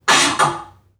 NPC_Creatures_Vocalisations_Robothead [30].wav